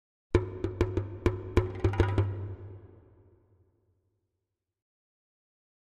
Drums Short African Dance 3 - Slow